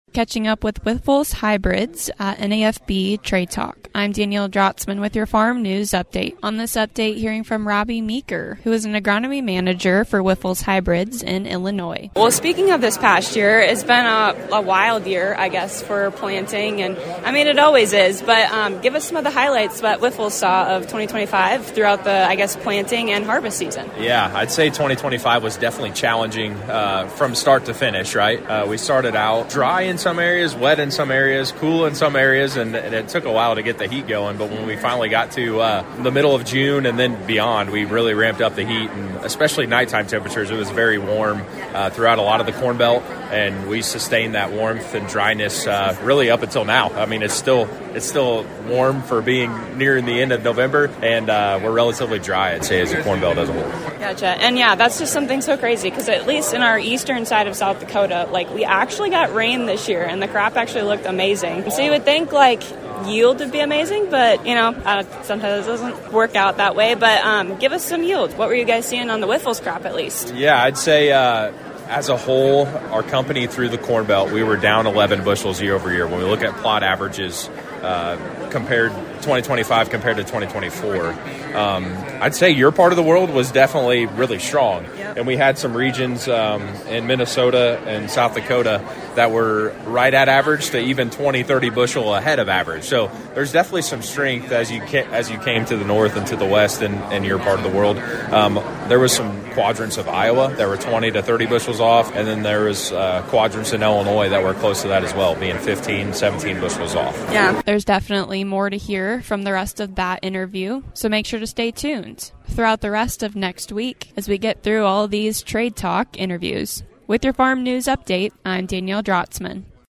Catching up with Wyffels Hybrids at the National Association of Farm Broadcasting convention.